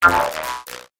Звук перед смертью батареи